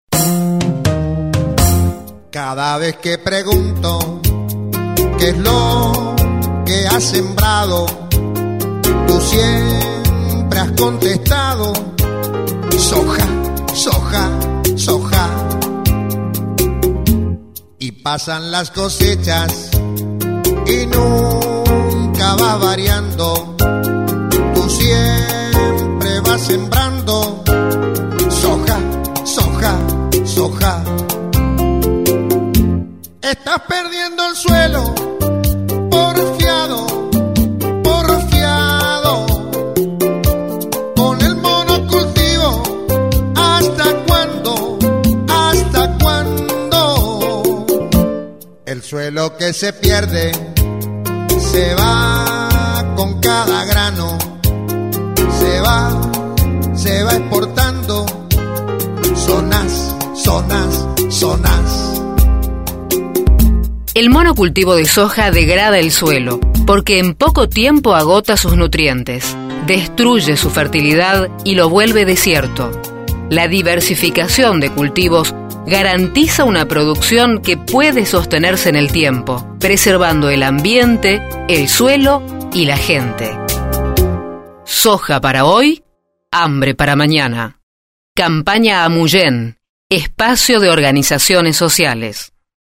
(spot radial) SOJA para hoy, hambre para mañana - QUIZÁS – Central de Trabajadores y Trabajadoras de la Argentina
Campaña radiofónica